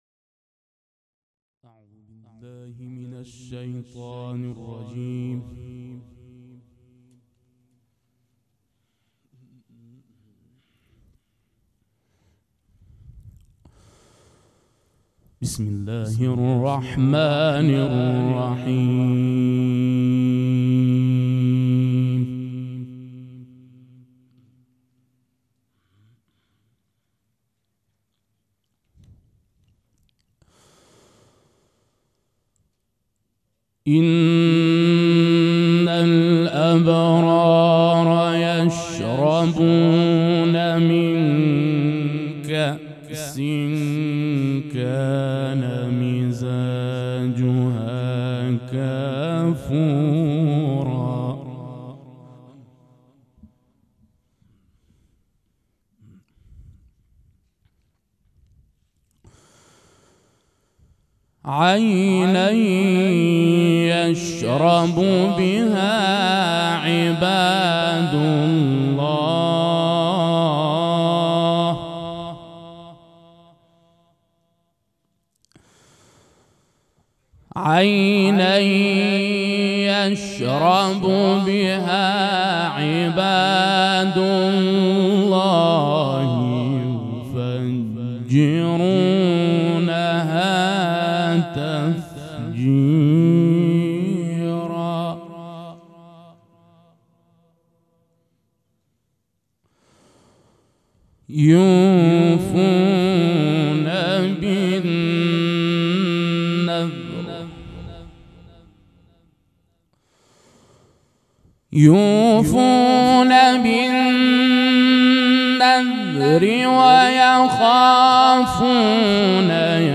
قرائت قرآن کریم
دانلود تصویر قرائت قرآن کریم favorite مراسم مناجات شب بیست و سوم ماه رمضان قاری
سبک اثــر قرائت قرآن